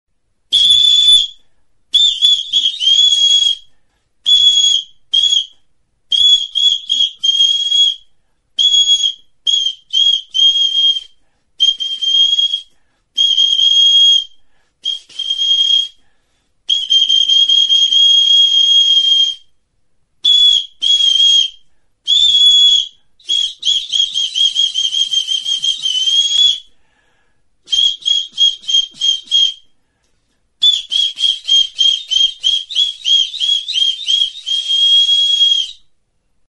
Aerophones -> Flutes -> Bestelakoak
Recorded with this music instrument.